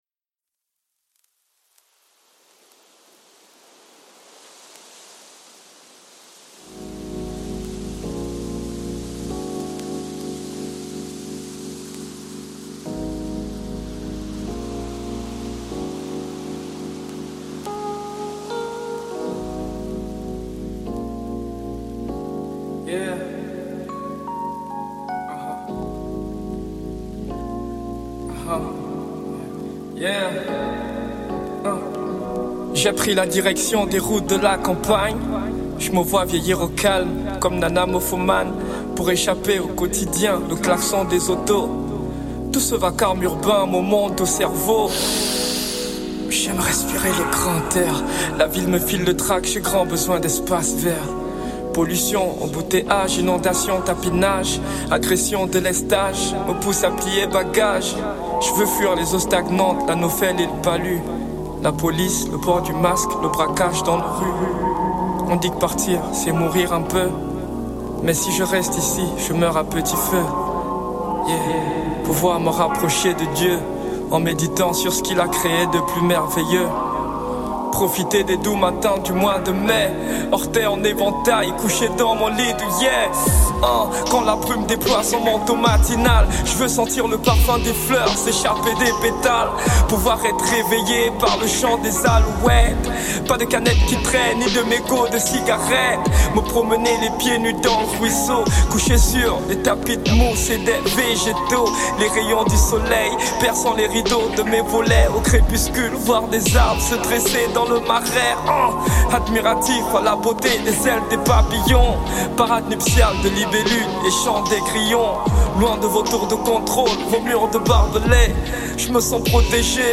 Genre : HipHop